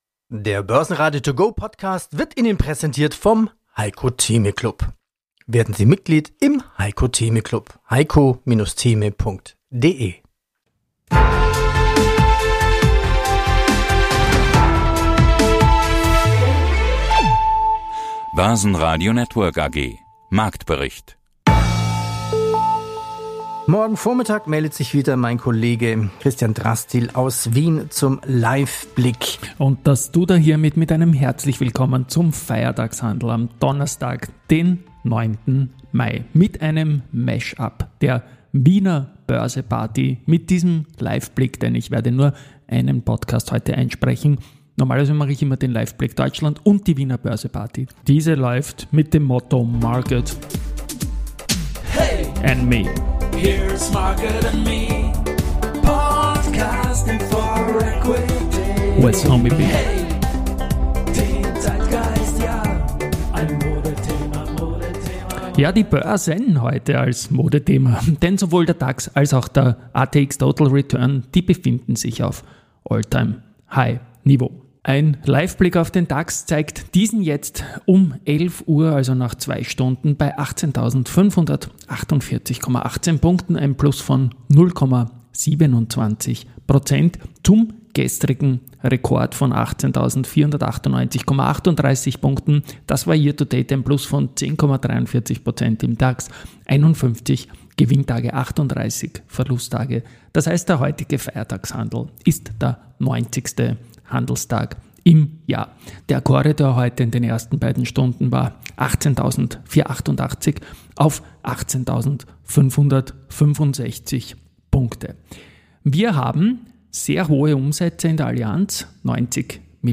Die Börse zum hören: mit Vorstandsinterviews, Expertenmeinungen und Marktberichten.